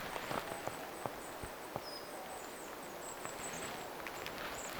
tuollainen kuusitiaisen ääniä
tuollainen_kuusitiaisen_aani.mp3